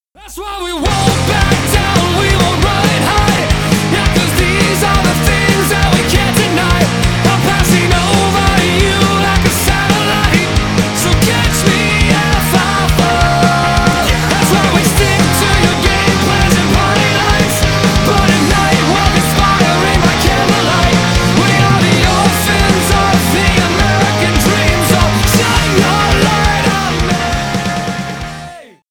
Рок Металл